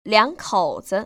[liăngkŏu‧zi] 리앙커우즈  ▶